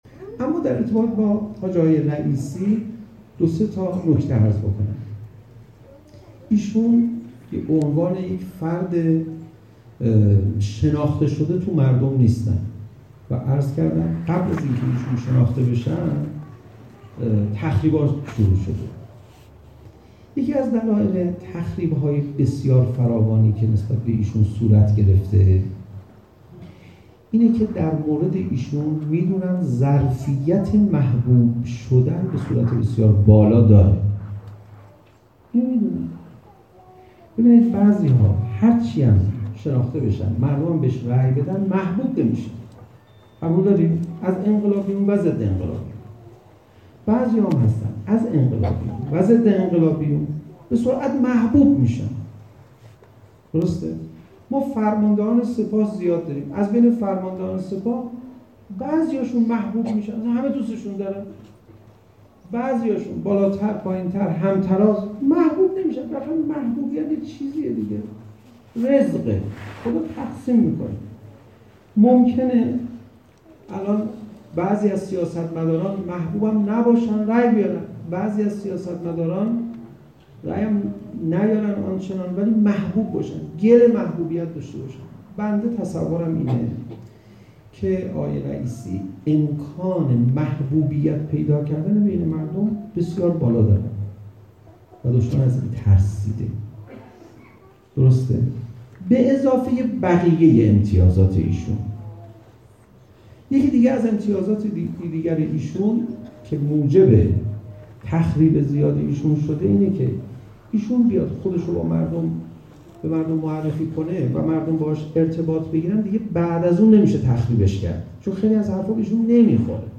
رجانیوز-گروه سیاسی: حامیان رئیسی این بار در مدرسه معصومیه قم با شوری وصفناشدنی گرد هم آمدند تا حجت الاسلام پناهیان از وضعیت انتخابات کنونی بگوید و امتیازات برجسته و مهم حجت الاسلام و المسلمین سید ابراهیم رئیسی را بربشمرد. جمعیت حاضر در برنامه به گونهای بود که برخلاف پیشبینی قبلی برنامه از سالن به حیاط مدرسه منتقل شد.
بخشی از سخنرانی بسیار مهم و کلیدی پناهیان را در ادامه از نظر میگذرانید: